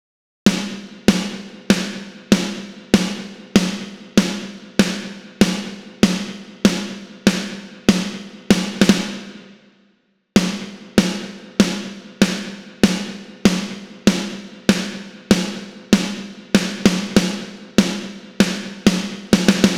Snare-No-Comp-2
Snare-No-Comp-2.wav